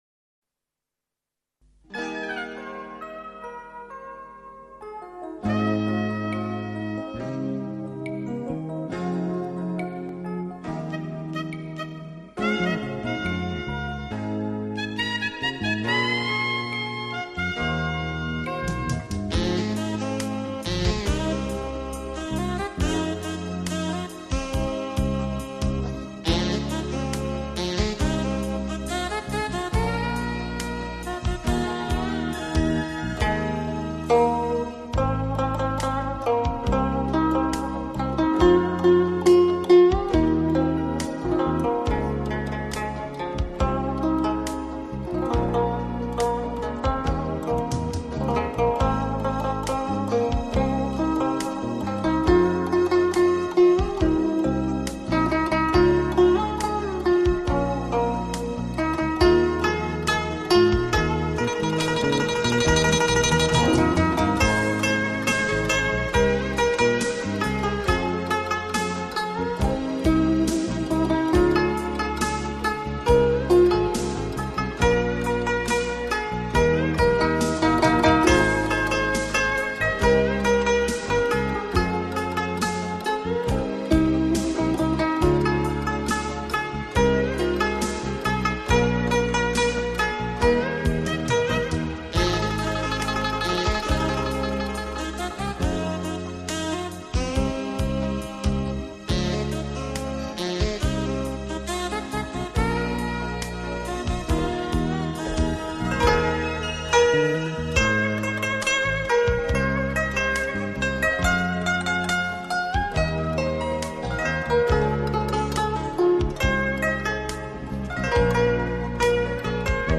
本专辑收录的是古筝流行名曲。
现代的流行音乐，用清新恬静的古老民族乐器演奏出来，配以现代乐器的多 变风格，的确在音韵间耕织灵气，让生命沉淀与此！
流行金曲采用古筝特有的音色来表现，当超人气 的现代流行音乐融入雅致甜美的古典气息，其韵味更是妙不可言。
纯音乐